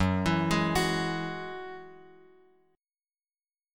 F#7sus2 chord {2 x 2 1 x 2} chord